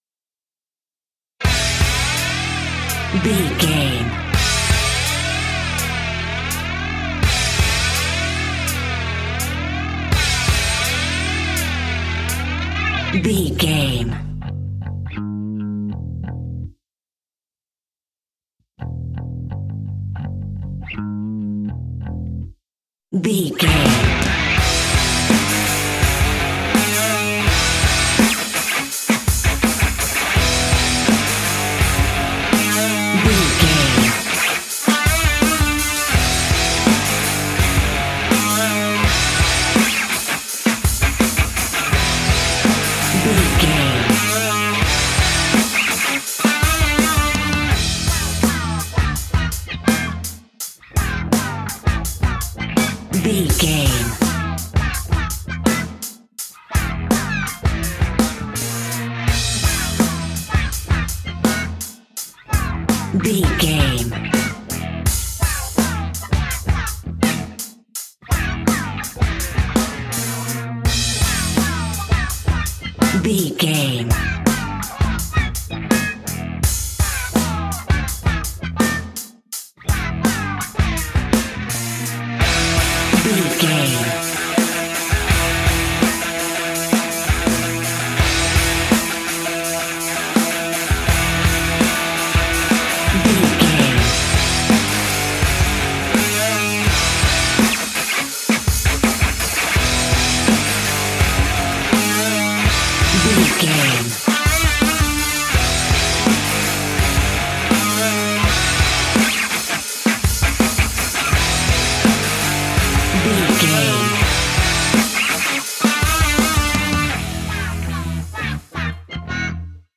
Epic / Action
Aeolian/Minor
hard rock
guitars
rock instrumentals
Heavy Metal Guitars
Metal Drums
Heavy Bass Guitars